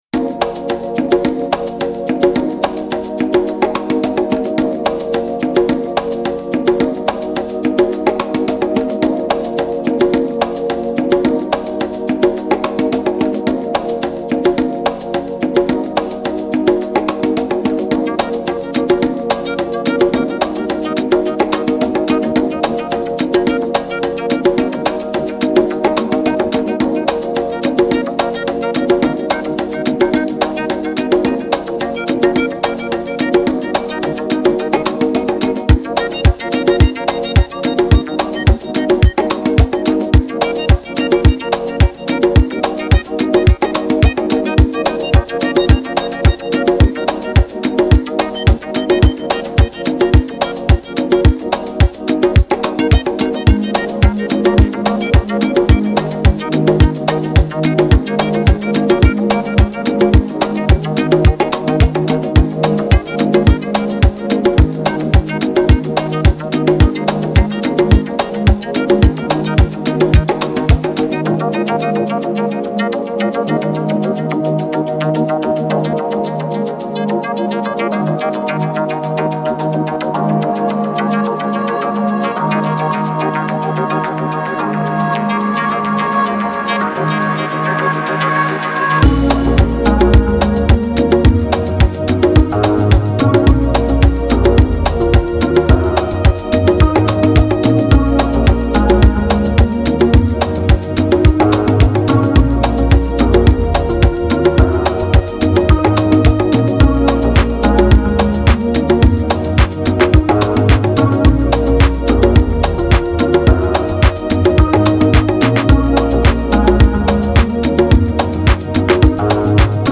Genre: Chillout, Deep House.